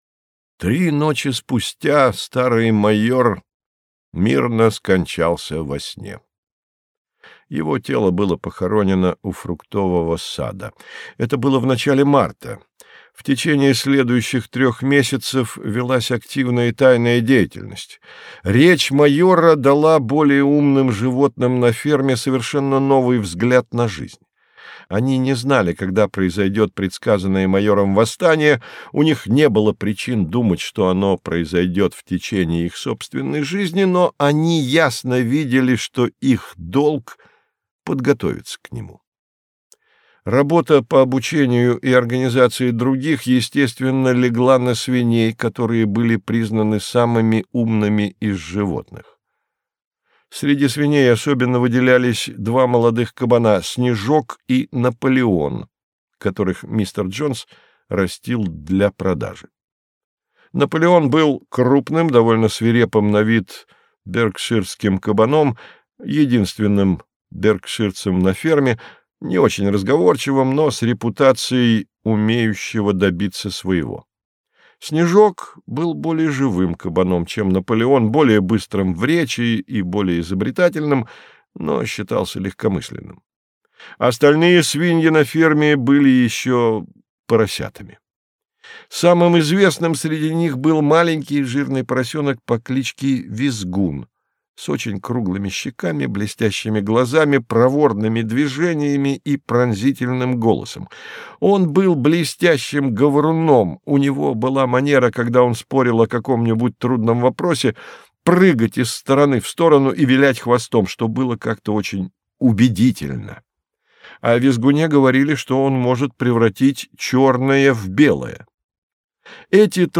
Аудиокнига Скотный Двор | Библиотека аудиокниг